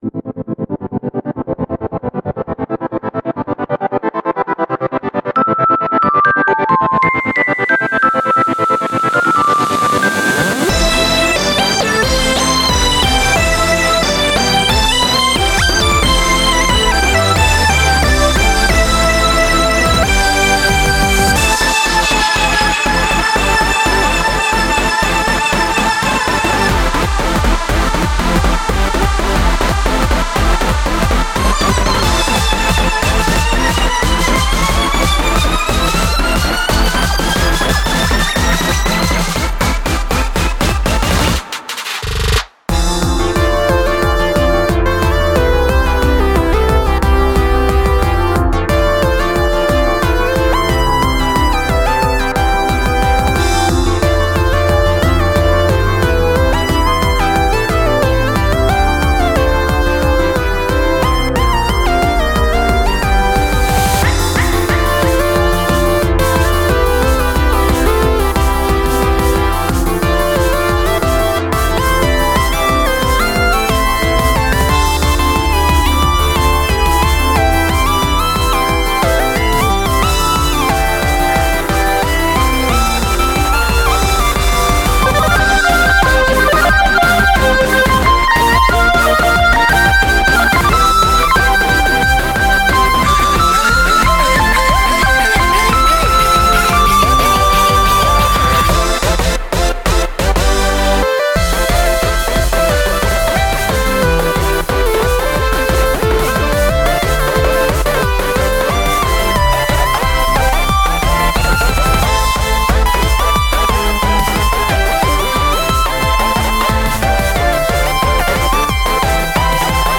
BPM90-180
Audio QualityPerfect (High Quality)
A breezy glide at a fast pace!